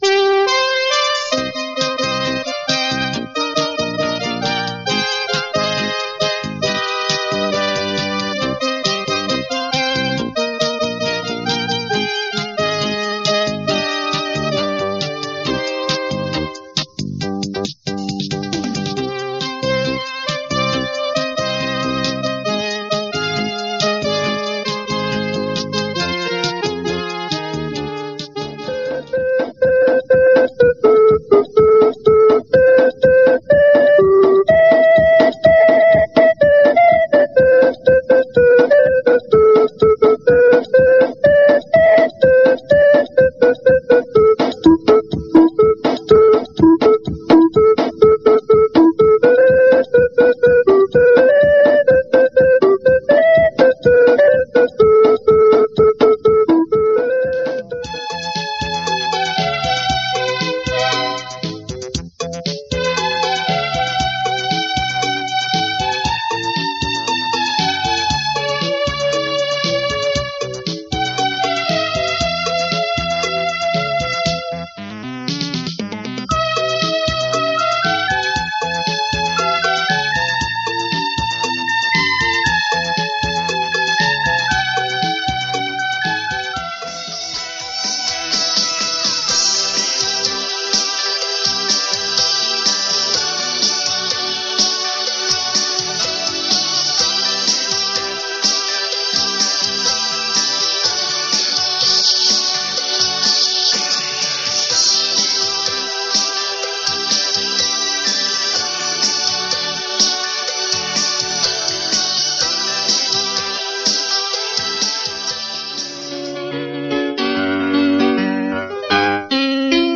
In this first year of the event, songs with vocals were not permitted - only instrumentals.